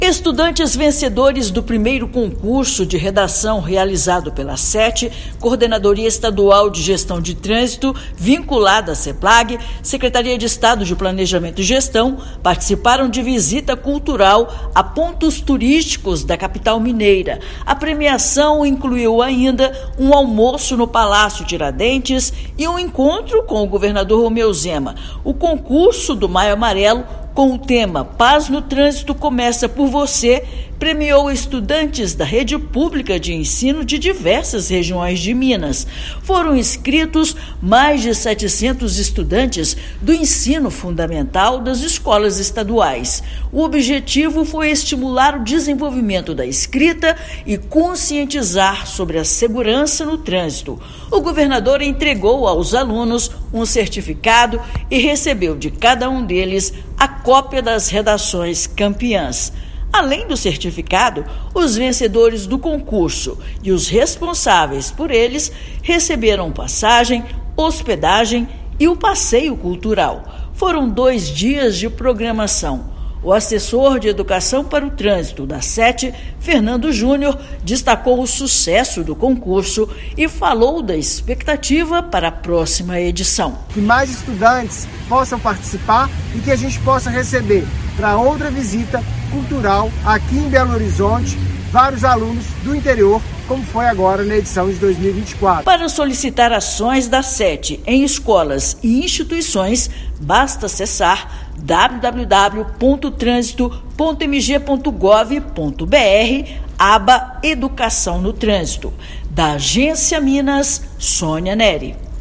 Programação também incluiu um almoço no Palácio Tiradentes e um encontro com o governador de Minas Gerais, Romeu Zema. Ouça matéria de rádio.